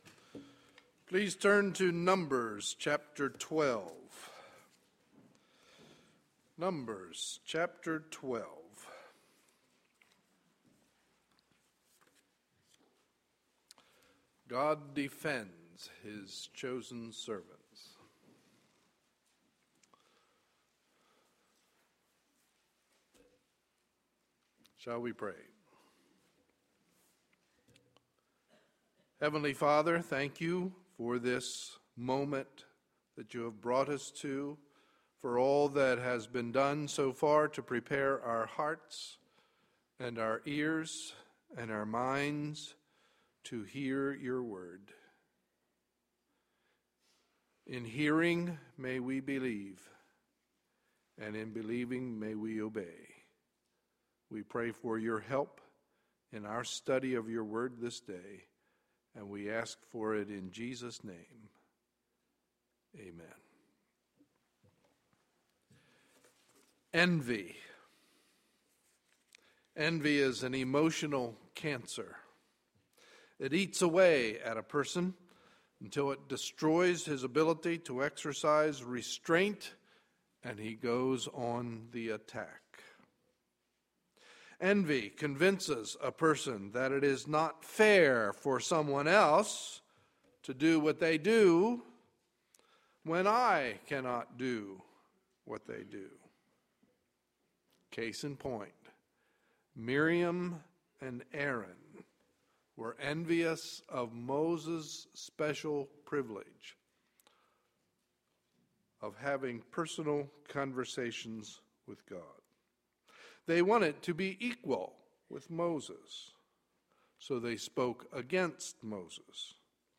Sunday, April 14, 2013 – Morning Service